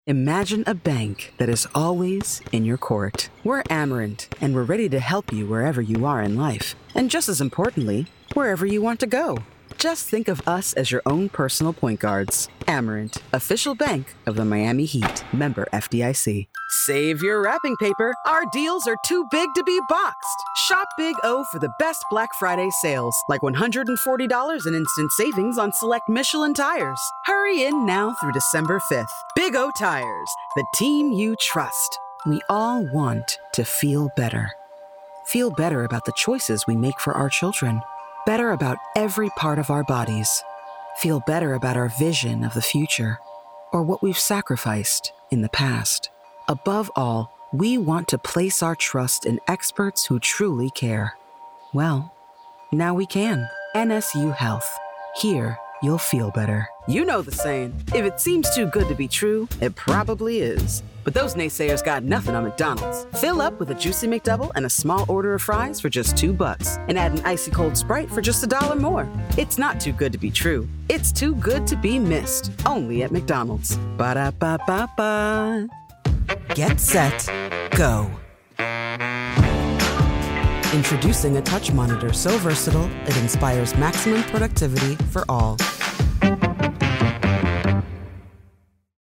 Demo
Child, Teenager, Young Adult, Adult, Mature Adult
non-broadcast level home studio